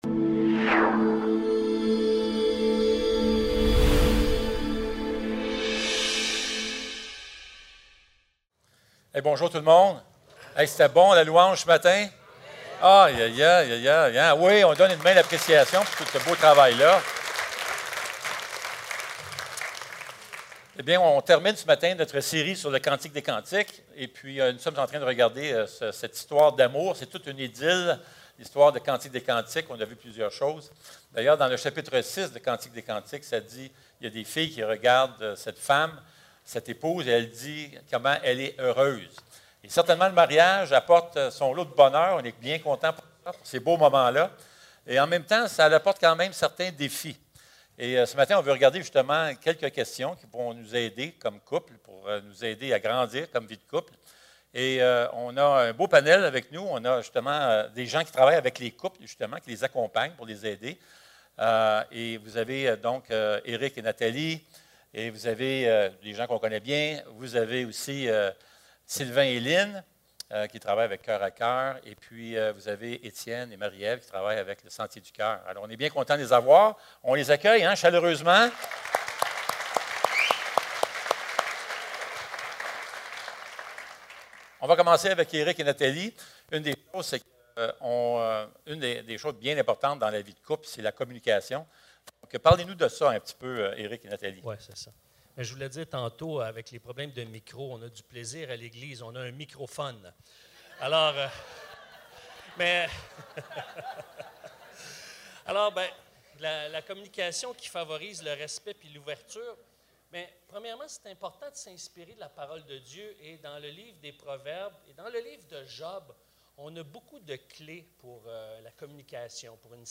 Sa bannière, c'est l'amour (4) - Panel d'invités < église le Sentier | Jésus t'aime!